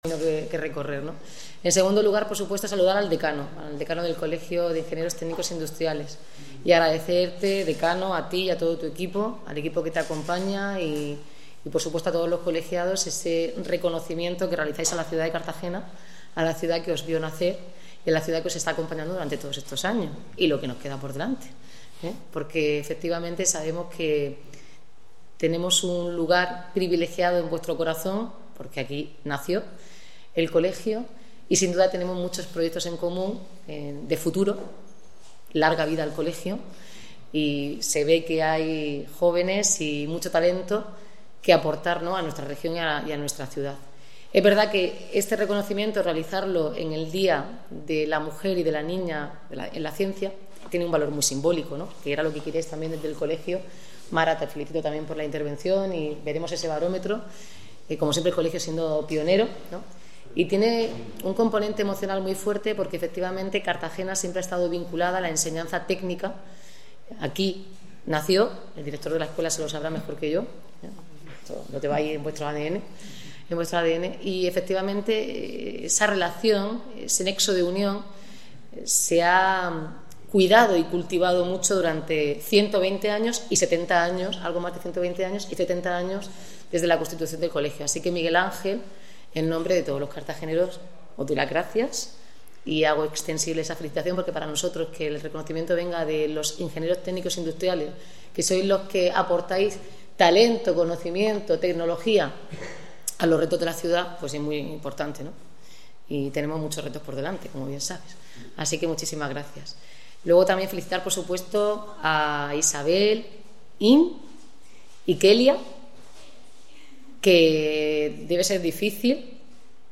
Audio: Declaraciones de la alcaldesa Noelia Arroyo y el rector Mathieu Kessler.
La alcaldesa de Cartagena, Noelia Arroyo, ha recibido esta mañana la Insignia de Oro y la Placa conmemorativa del 70 aniversario del Colegio Oficial de Ingenieros Técnicos Industriales de la Región de Murcia (COITIRM), en un acto celebrado en el Salón de Grados de la Escuela Técnica Superior de Ingeniería Industrial de la UPCT, enmarcado en la conmemoración del Día Internacional de la Mujer y la Niña en la Ciencia.